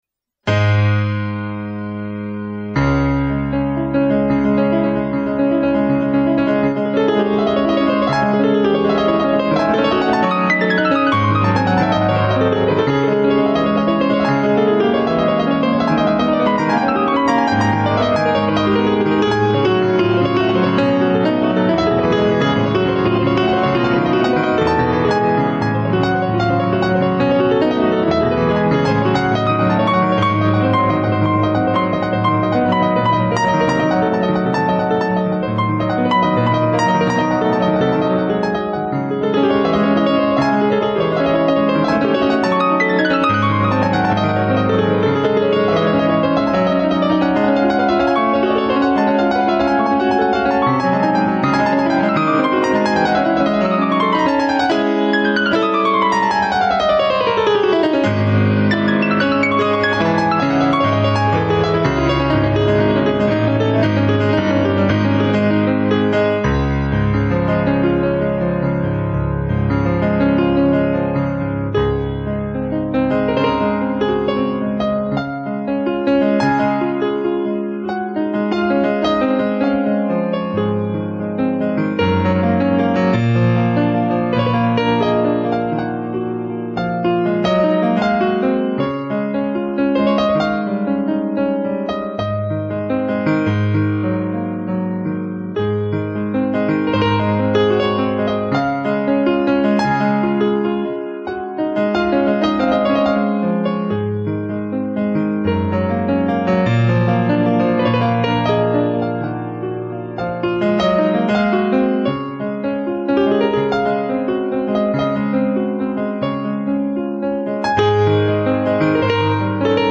[26/4/2009]钢琴《幻想即兴曲》